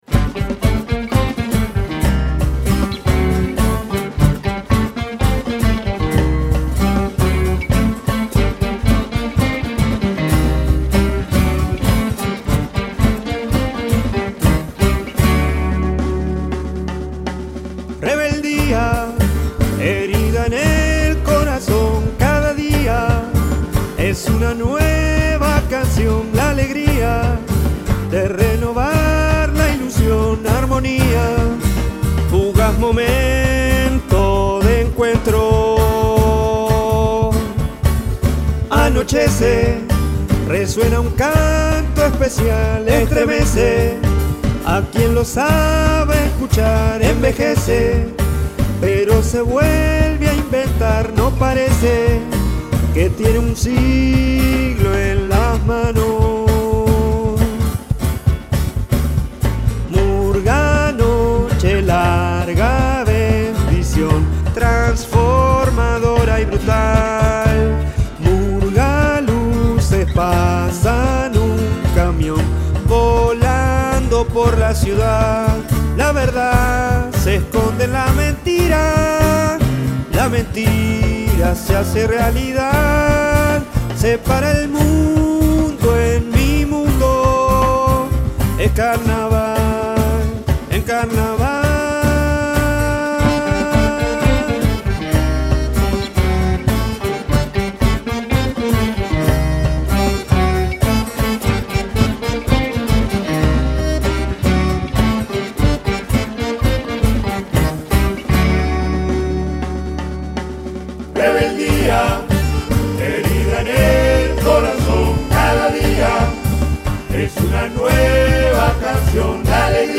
Categoría murga